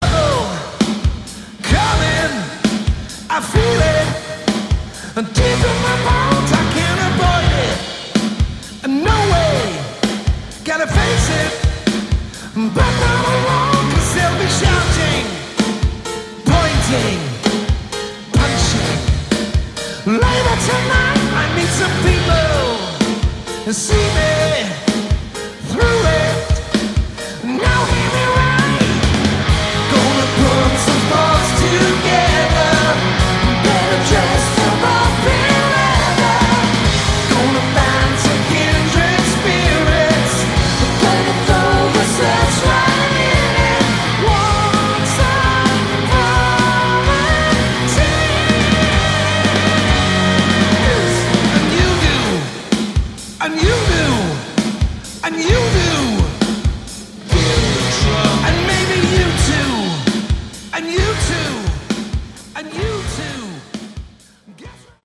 Category: Hard Rock
vocals, guitar, keyboards
drums
bass